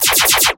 Звуки бластера
Сккоорострельные выстрелы автоматной очередью